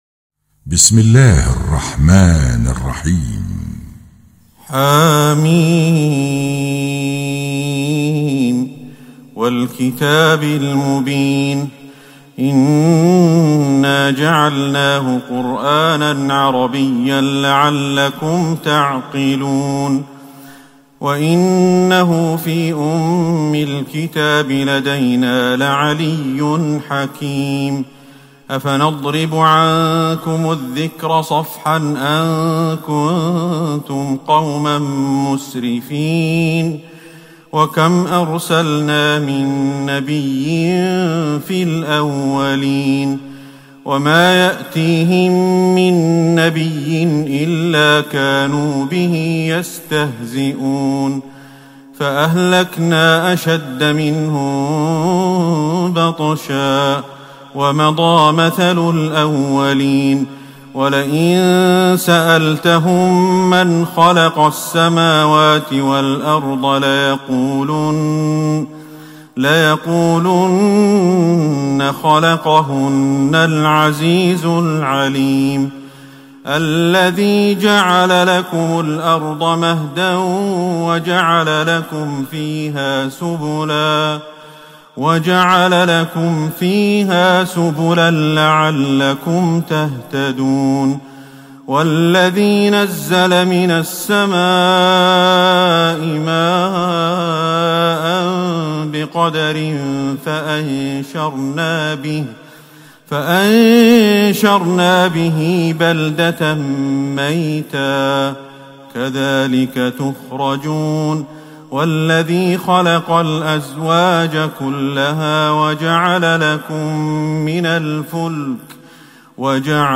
تهجد ٢٦ رمضان ١٤٤١هـ سورة الزخرف كاملة والدخان ١-١٦ > تراويح الحرم النبوي عام 1441 🕌 > التراويح - تلاوات الحرمين